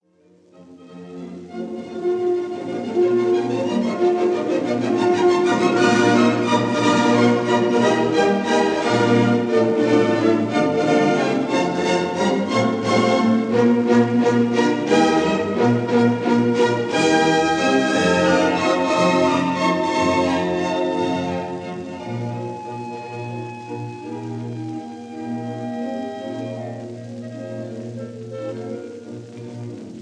Allegro vivace in a 1947 recording